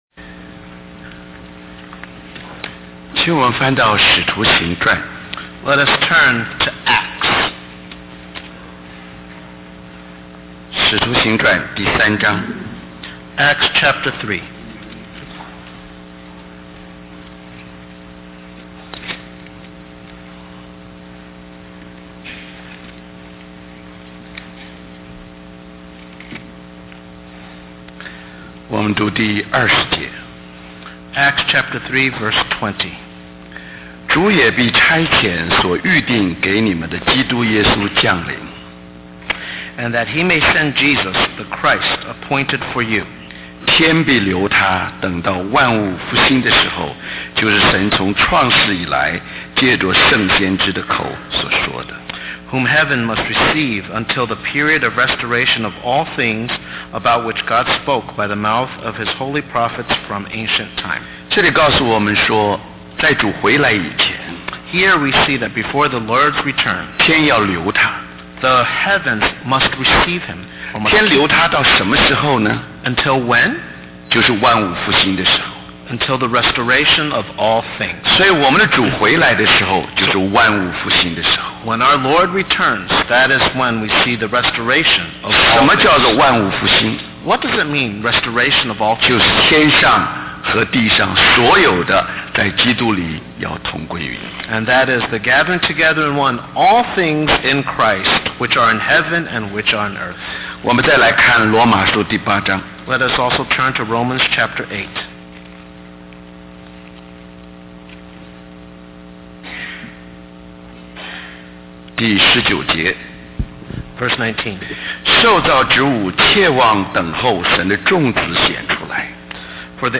Special Conference For Service, Taipei, Taiwan